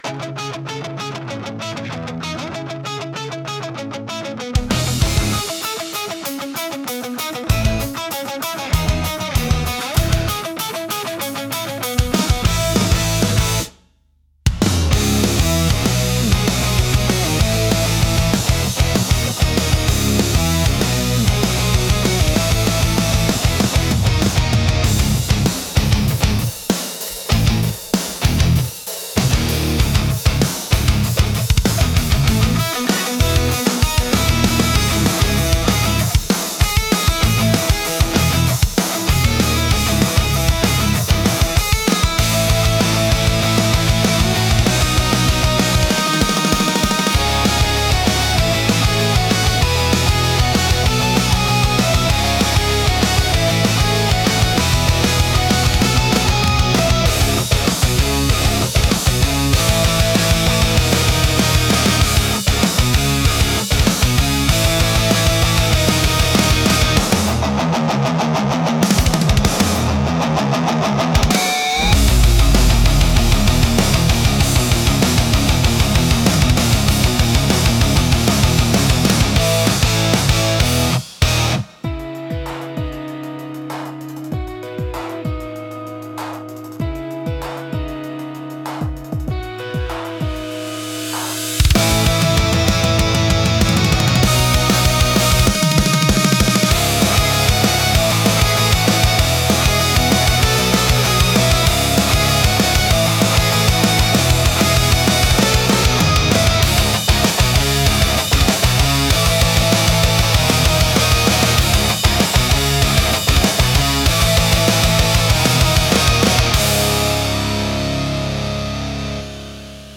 Raw Extreme Rock